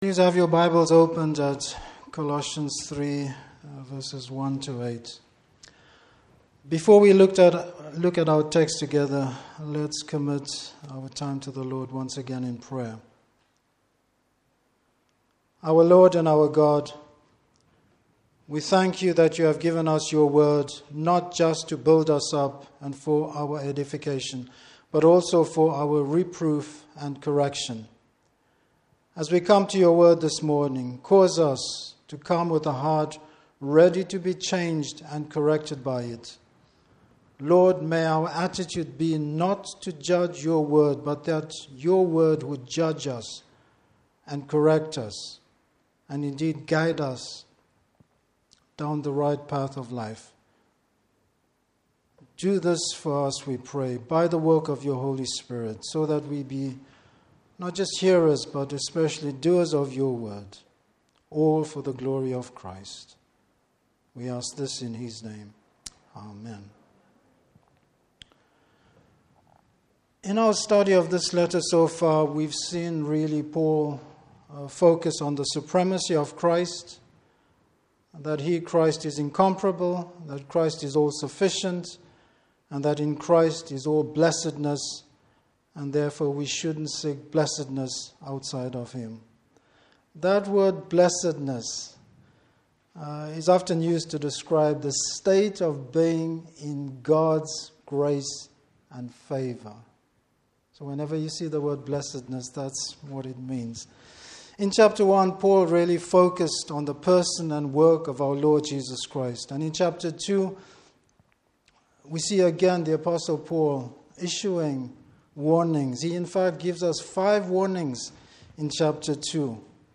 Service Type: Morning Service The putting to death of the former self.